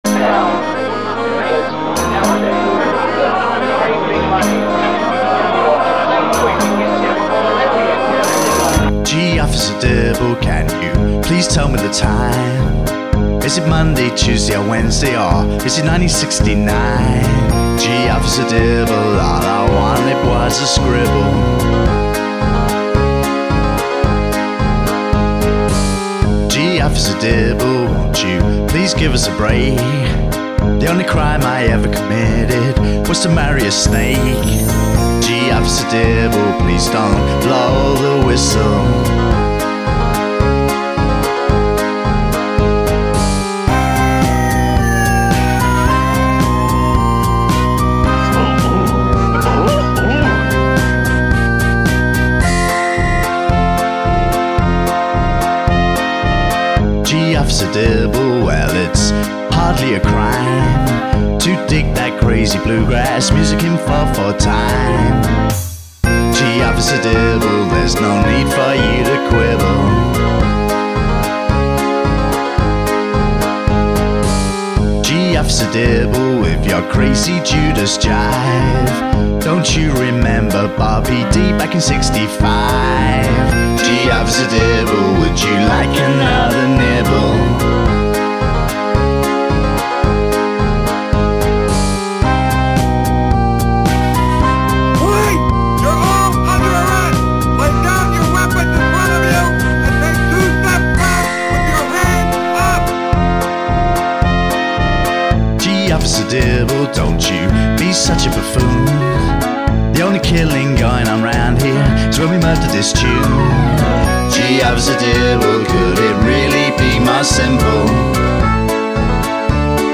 A post punk pop group from Cornwall